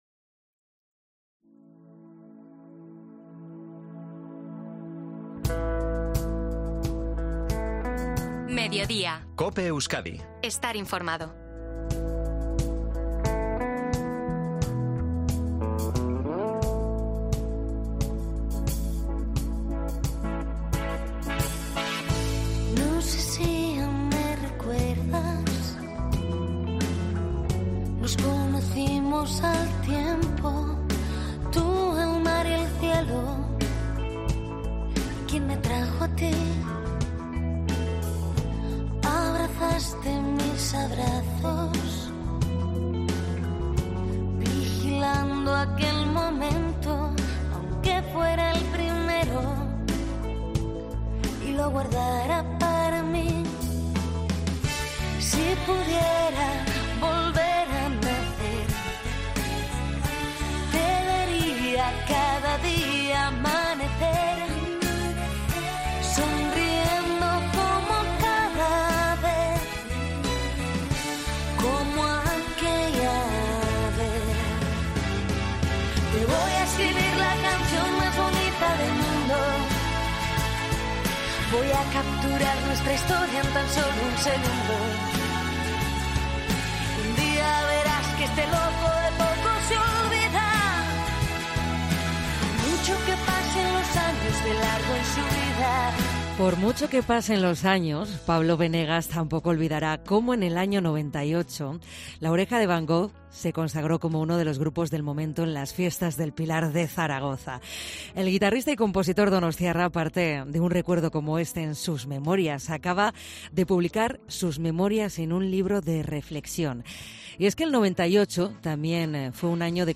Pablo Benegas, en COPE Euskadi